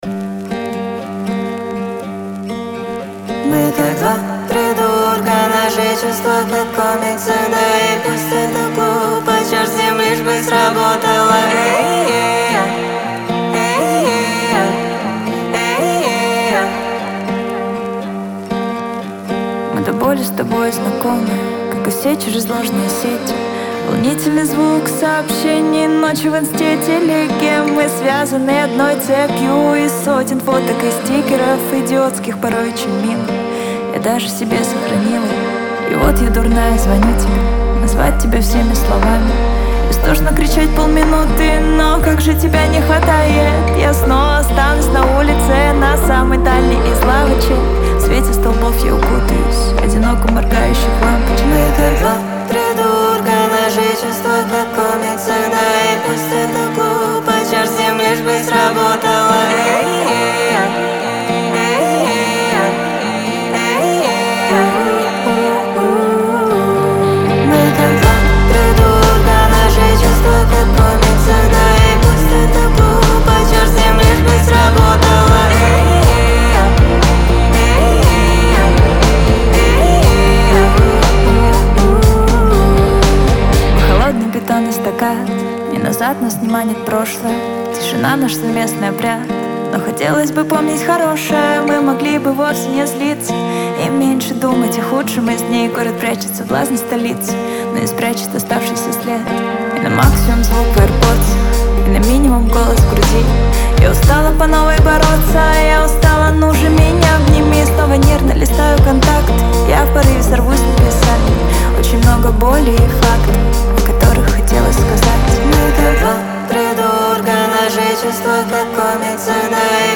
это яркая композиция в жанре поп-рок